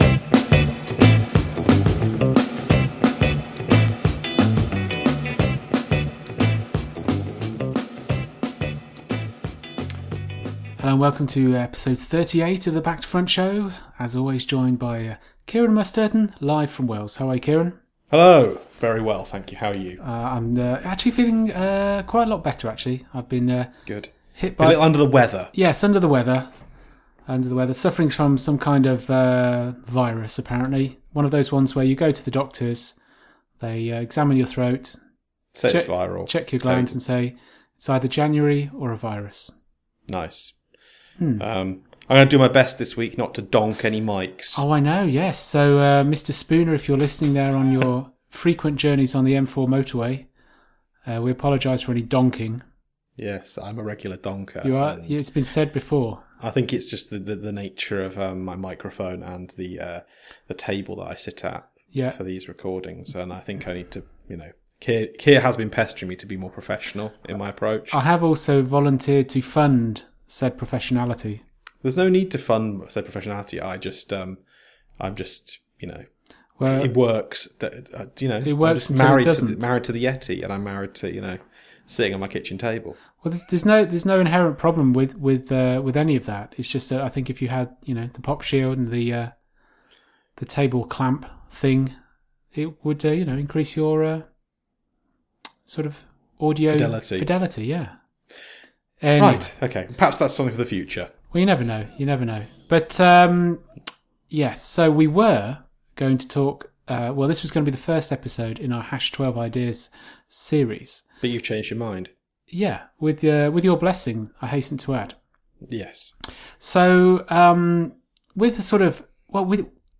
Welcome to Back Stories — a new series of one on one interviews